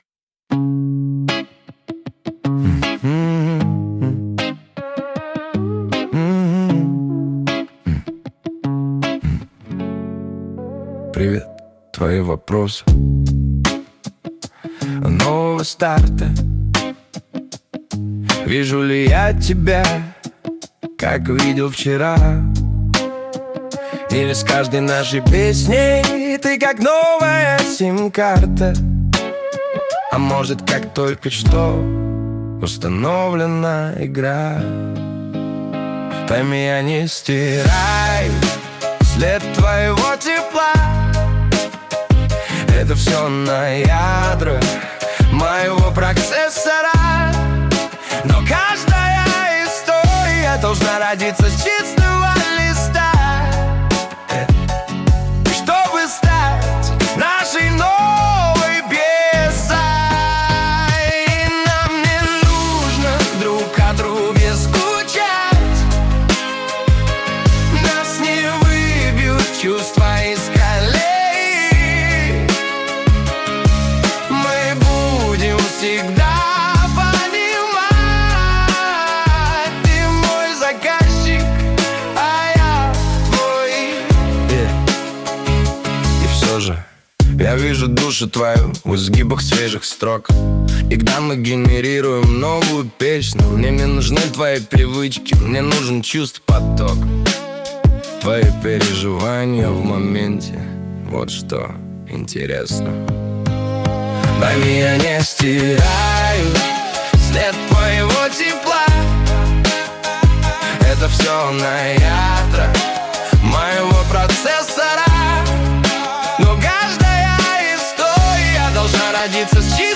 ..Я создаю песни с помощью ИИ исключительно на основе собственных стихов, которые рождаются на чувствах, эмоциях, переживаниях и опыте.